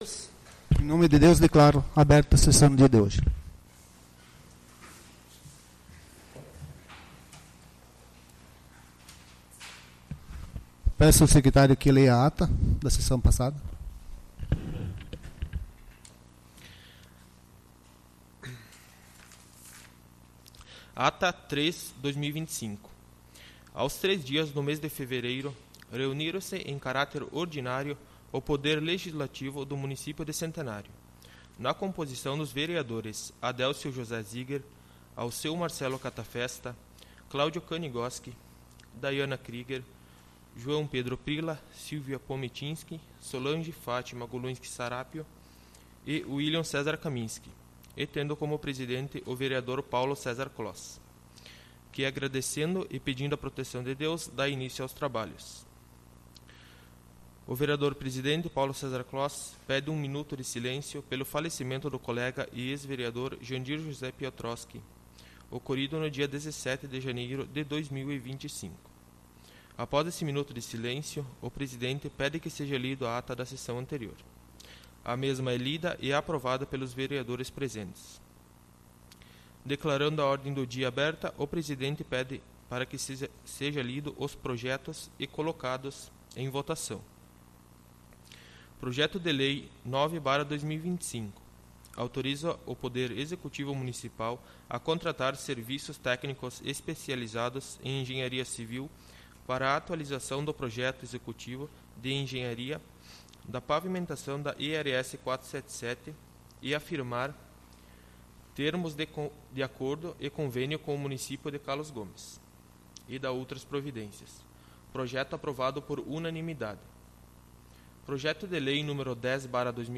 Sessão Ordinária 17/02/2025